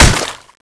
Index of /server/sound/weapons/tfa_cso/m249ep
hit2.wav